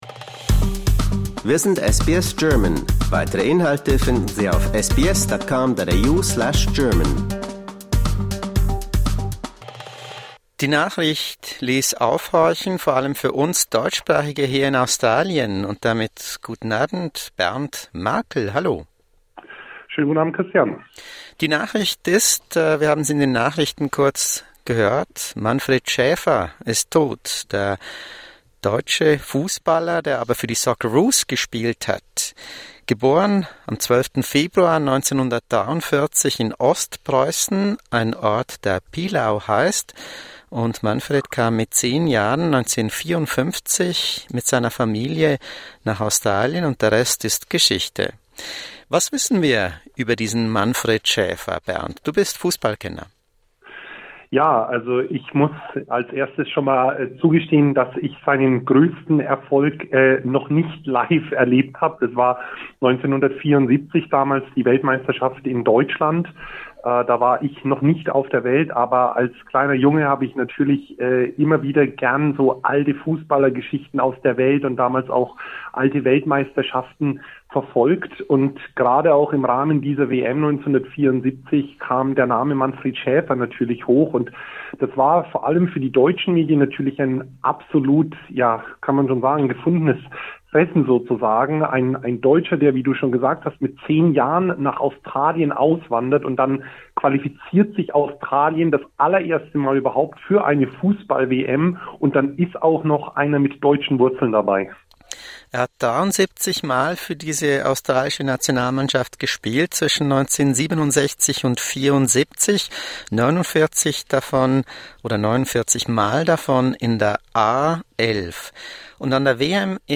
On the death of Manfred Schaefer, an SBS interview